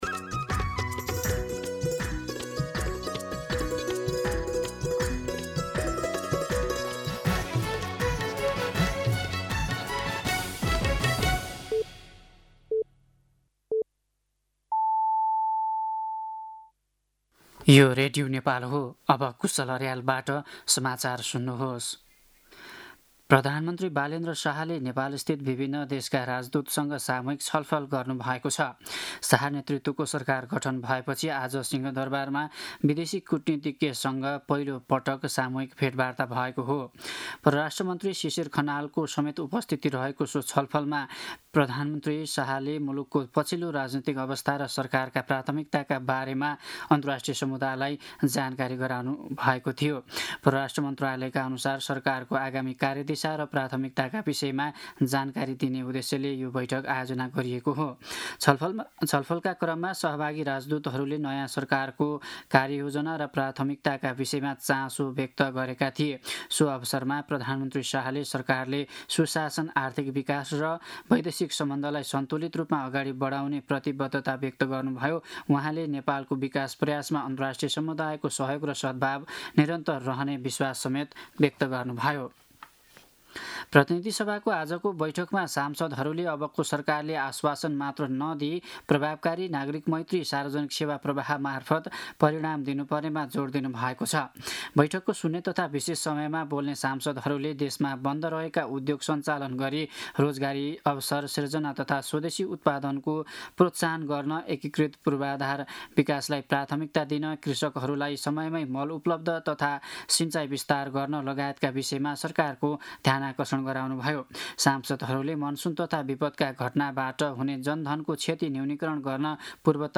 दिउँसो ४ बजेको नेपाली समाचार : २५ चैत , २०८२